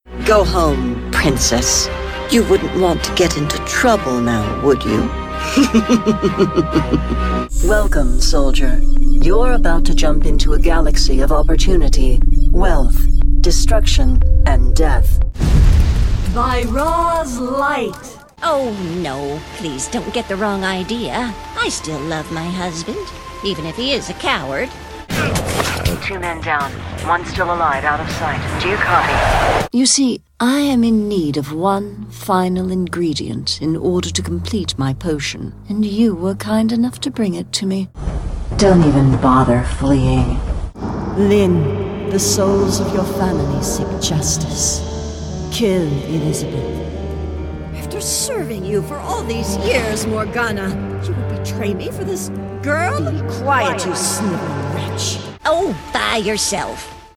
Experienced female voice artist with a rich, textured sound, authoritative yet warm
mid-atlantic
Sprechprobe: Sonstiges (Muttersprache):